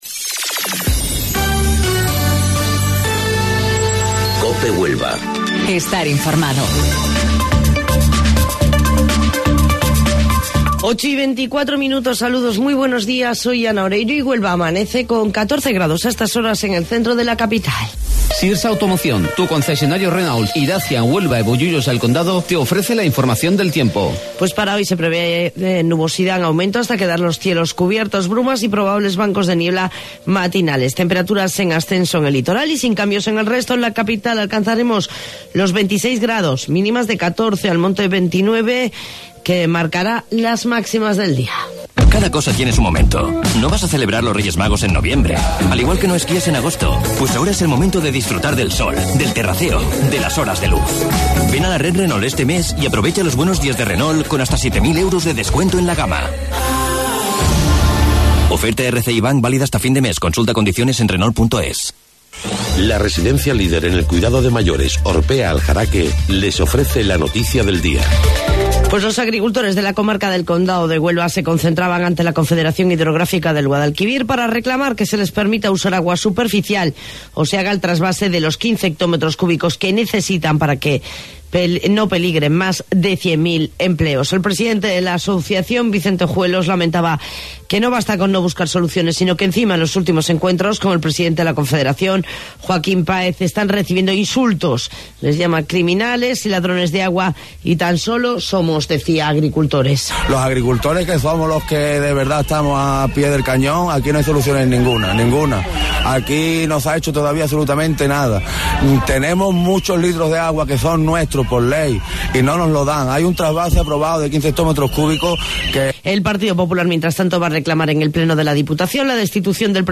AUDIO: Informativo Local 08:25 del 7 de Mayo